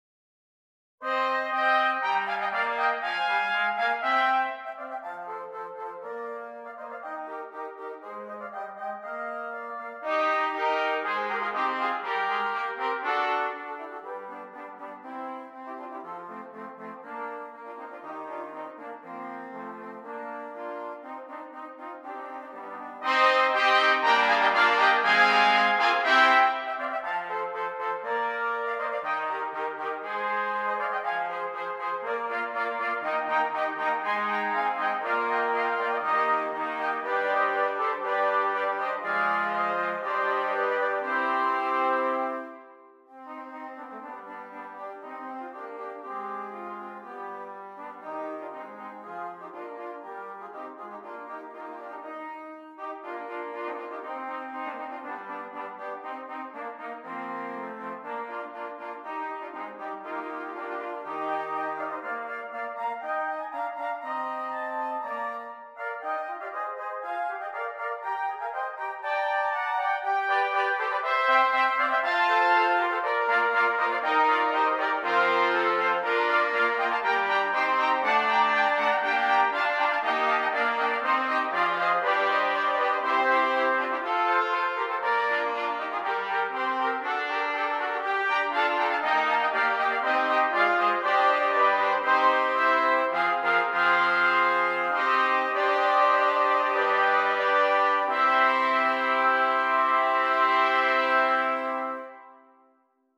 Brass
6 Trumpets